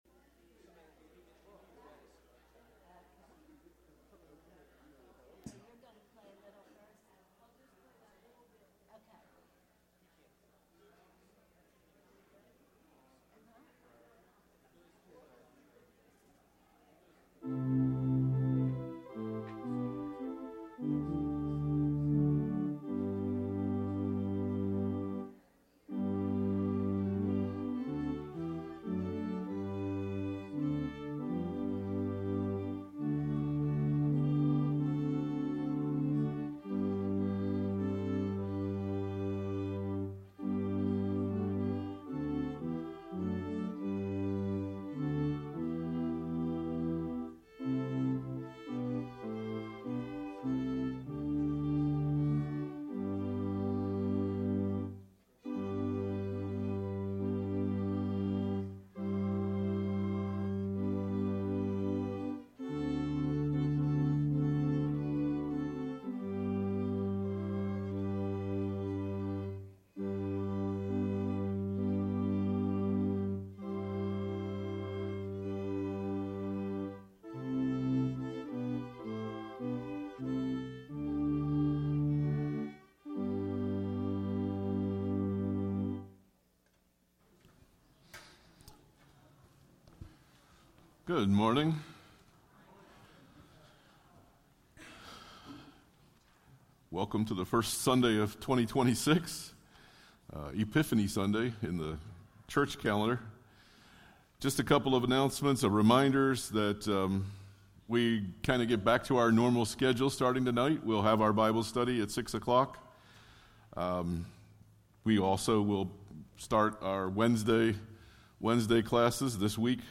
Sermons by Palmyra First EC Church